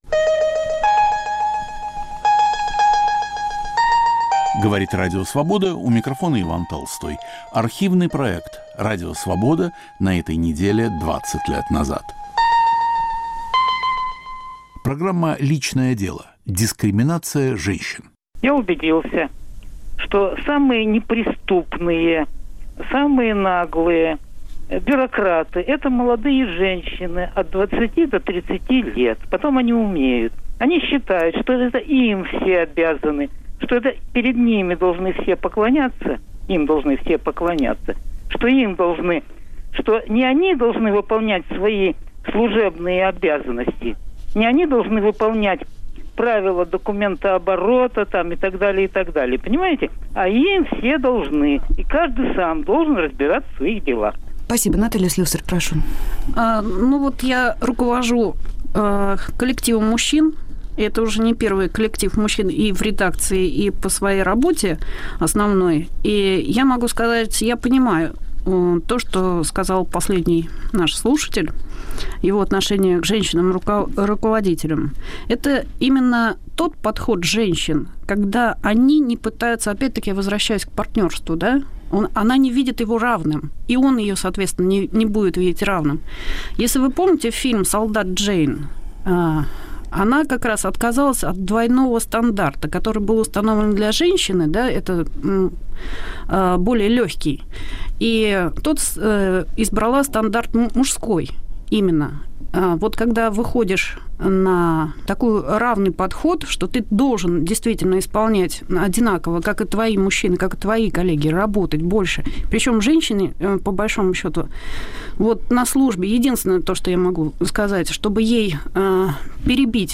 Гости в студии